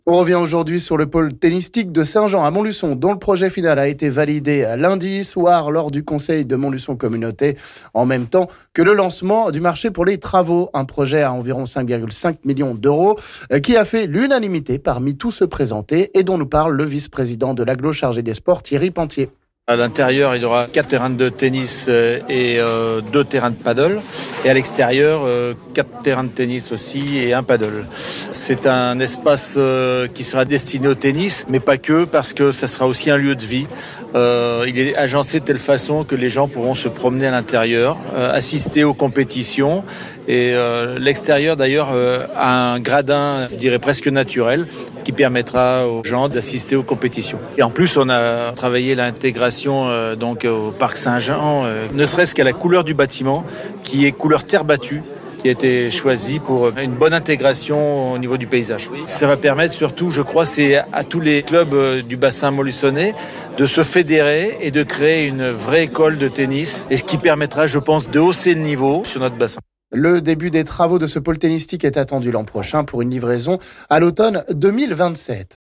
Le vice-président de l'agglo en charge des sports Thierry Penthier nous en parle...